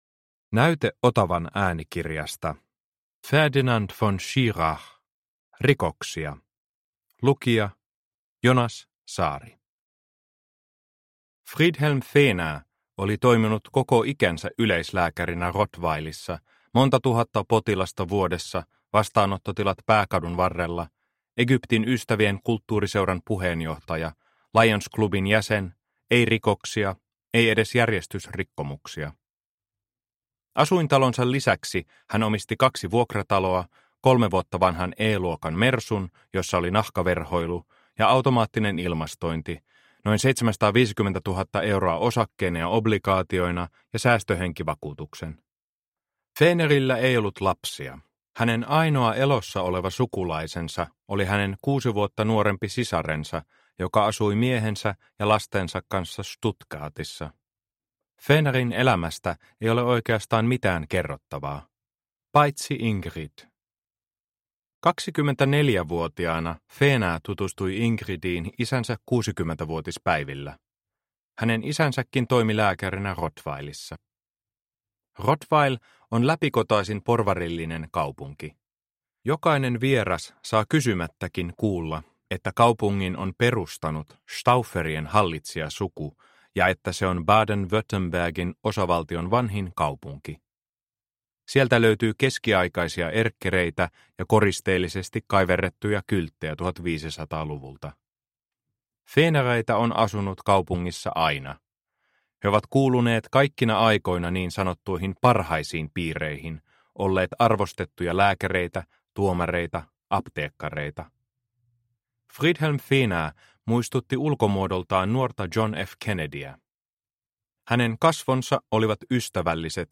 Rikoksia – Ljudbok – Laddas ner